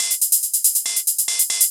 Index of /musicradar/ultimate-hihat-samples/140bpm
UHH_ElectroHatB_140-05.wav